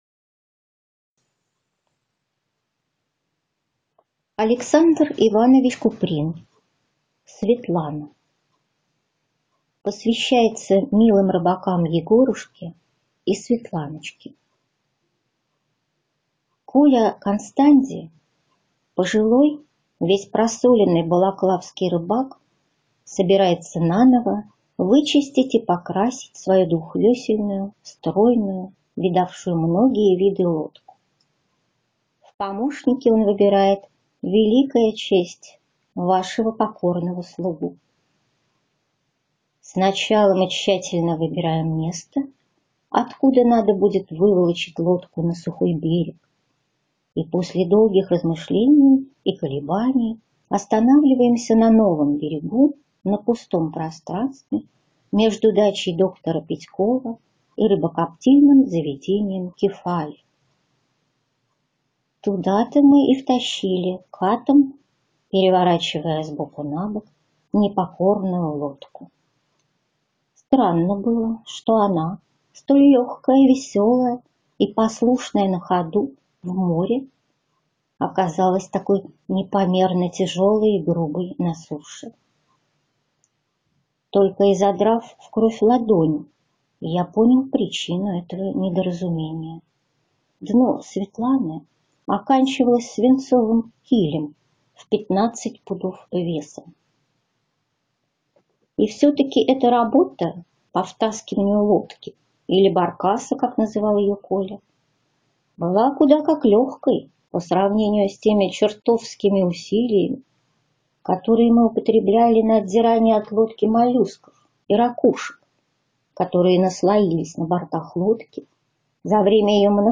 Аудиокнига Светлана | Библиотека аудиокниг